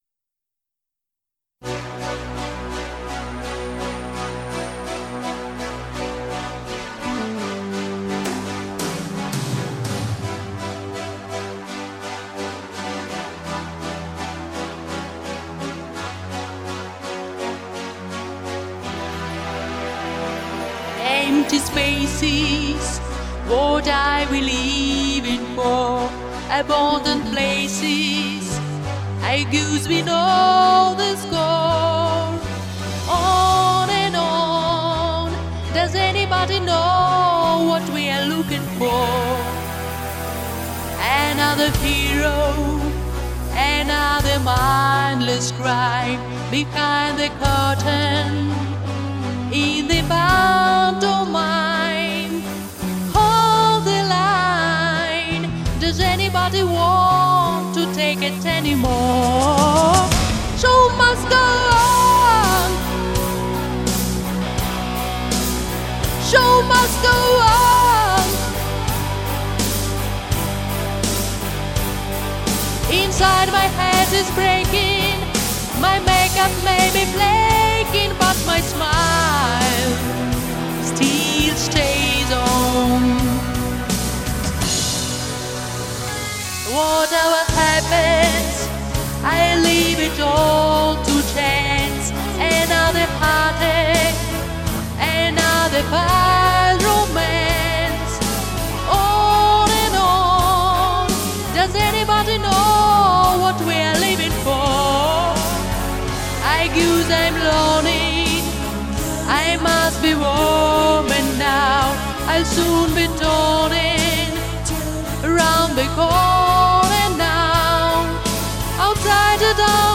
Получился очень красивый женский вокал...